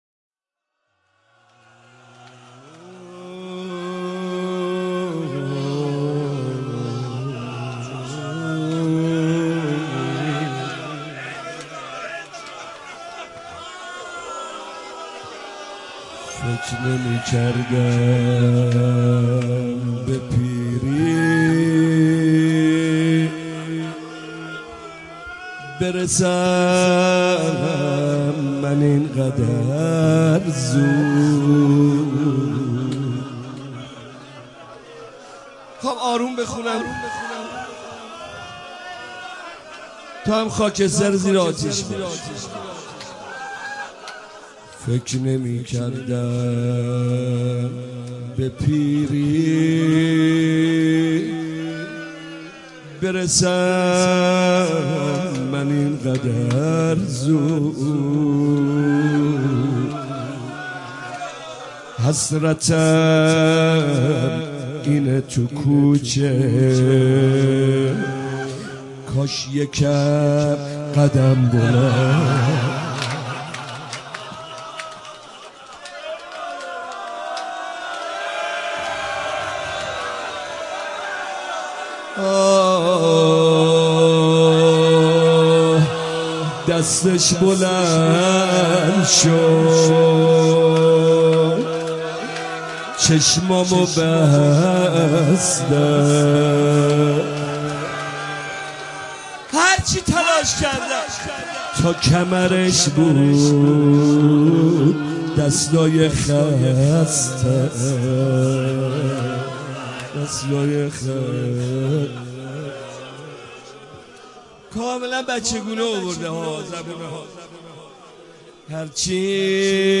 روضه حضرت زهرا (س) فکر نمی‌کردم‌ به‌ پیری‌ برسم محمدرضا طاهری ایام فاطمیه | ❤یک نت